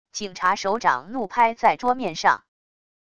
警察手掌怒拍在桌面上wav音频